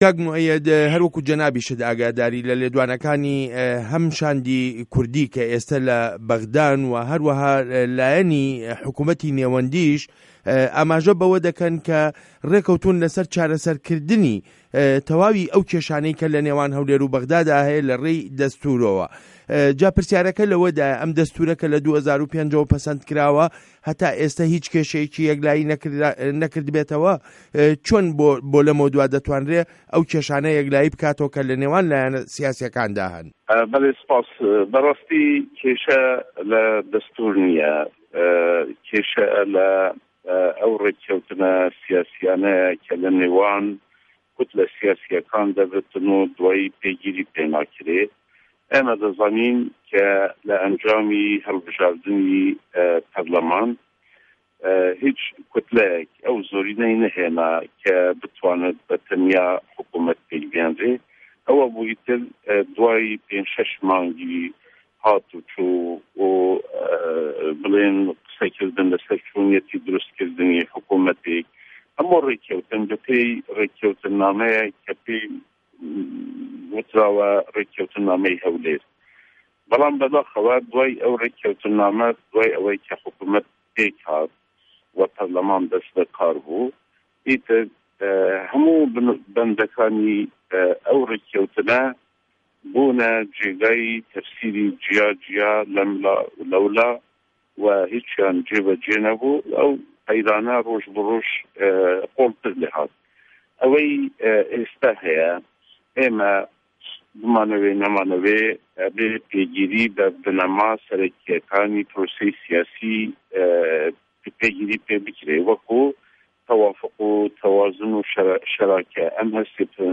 وتووێژ له‌گه‌ڵ موئه‌یه‌د ته‌یب